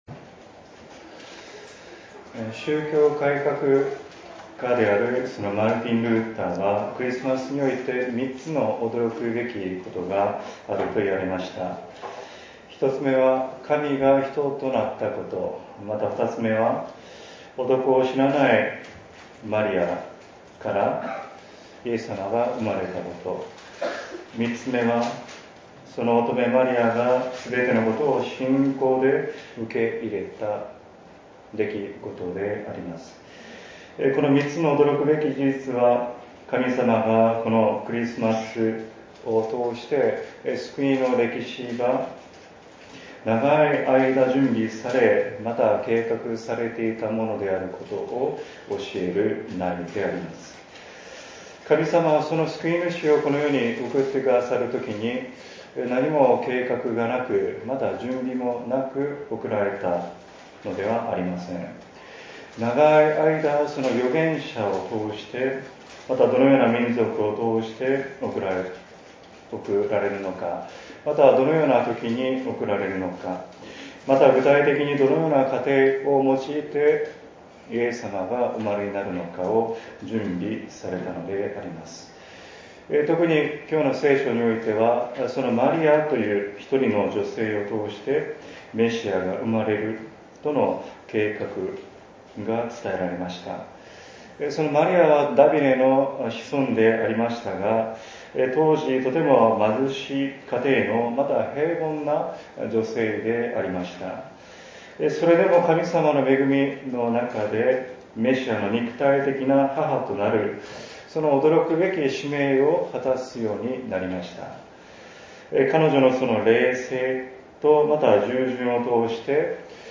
■クリスマス讃美礼拝 １２月２４日（日）の讃美礼拝は神様に守られ多くの方が参加してくださいました。